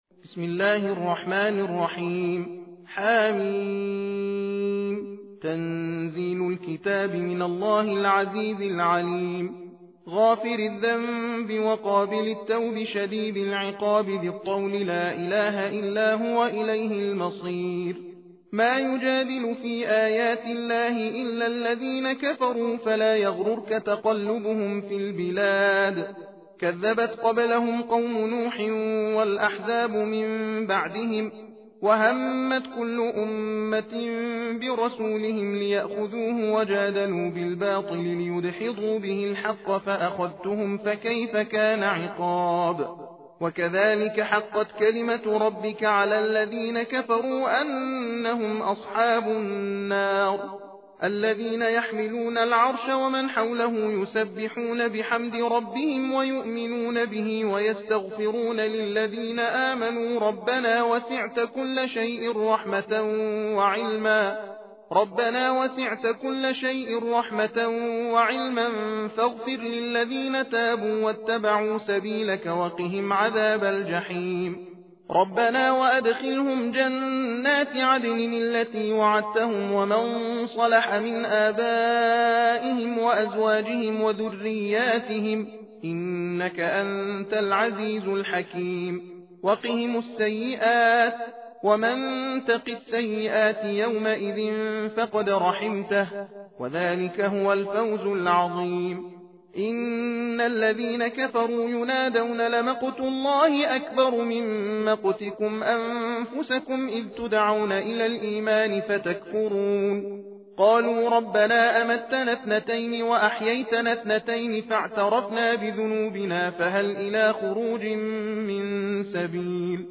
تحدیر (تندخوانی) سوره غافر
تحدیر روشی از تلاوت قرآن است که قاری در آن علی رغم رعایت کردن قواعد تجوید، از سرعت در خواندن نیز بهره می برد، از این رو در زمان یکسان نسبت به ترتیل و تحقیق تعداد آیات بیشتری تلاوت می شود.به دلیل سرعت بالا در تلاوت از این روش برای مجالس ختم قرآن کریم نیز می توان بهره برد.